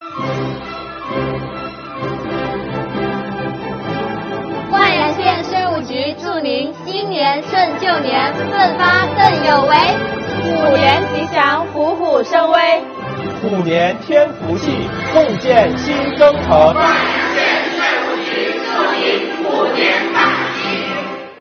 国家税务总局灌阳县税务局全体干部职工祝大家，虎年添福气，共建新征程！